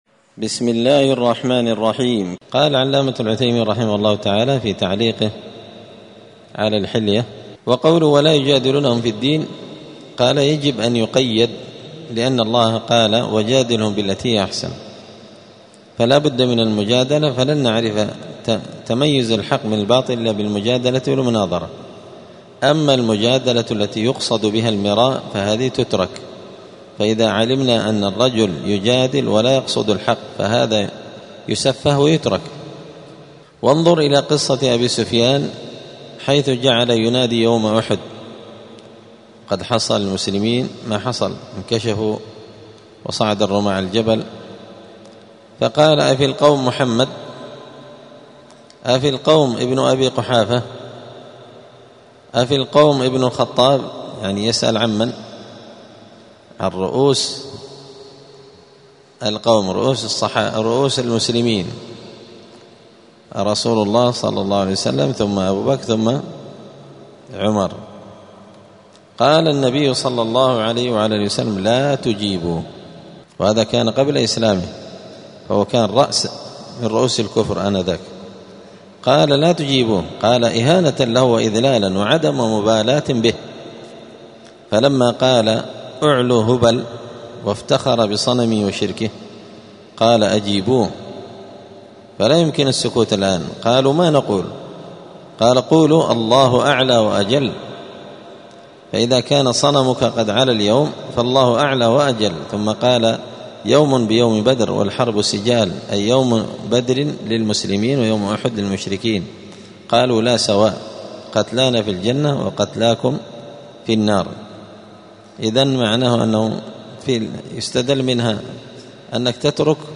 *الدرس السادس والأربعون (46) {المجادلة مع أهل البدع}.*